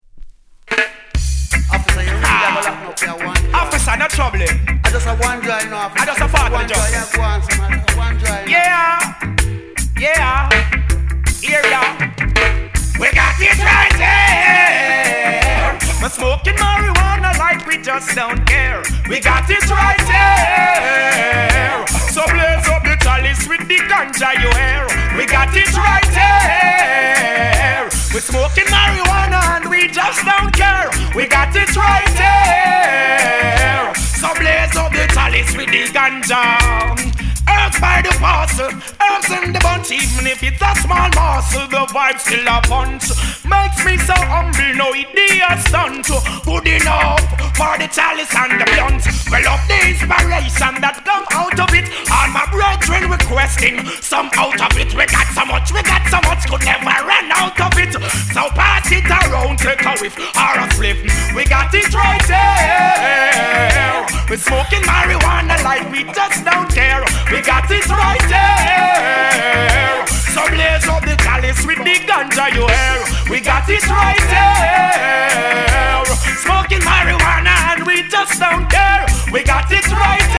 Genre: Reggae/ Dancehall